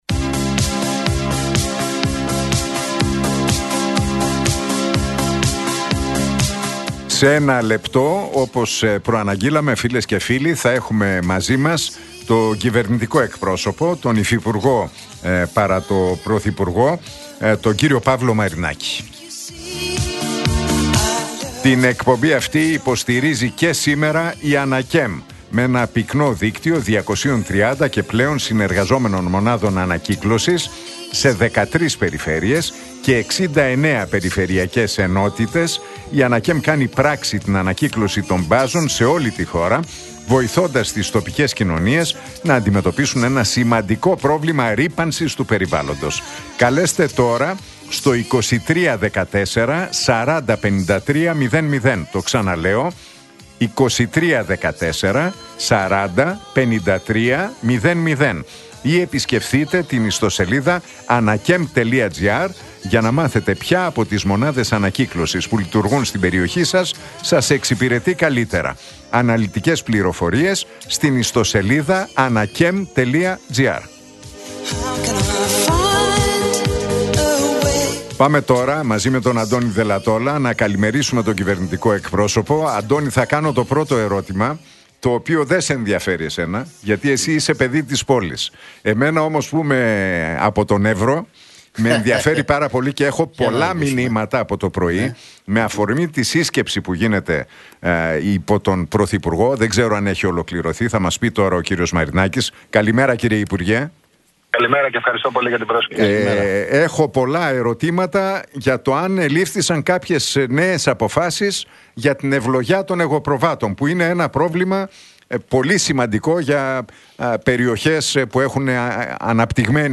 Ακούστε την εκπομπή του Νίκου Χατζηνικολάου στον ραδιοφωνικό σταθμό RealFm 97,8, την Τρίτη 27 Ιανουαρίου 2026.